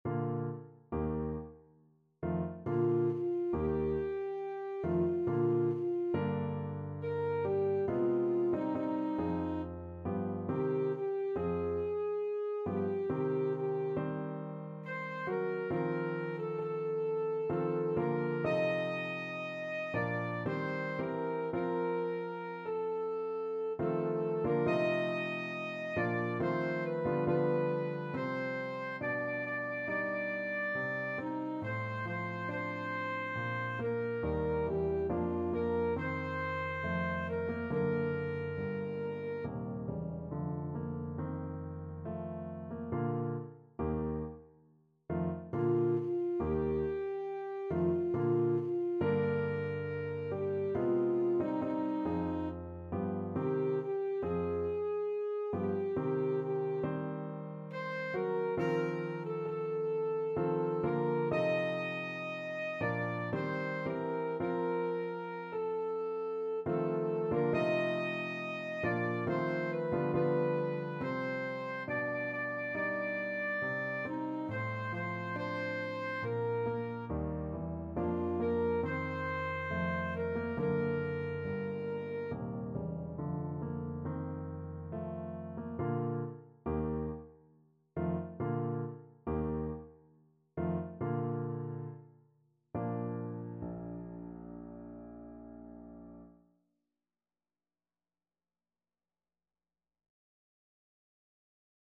Classical Schumann, Robert Seit ich ihn gesehen (No. 1 from Frauenliebe und Leben) Alto Saxophone version
Alto Saxophone
Bb major (Sounding Pitch) G major (Alto Saxophone in Eb) (View more Bb major Music for Saxophone )
3/4 (View more 3/4 Music)
Larghetto =69
Eb5-Eb6
Classical (View more Classical Saxophone Music)
schumann_seit_ich_ihn_ASAX.mp3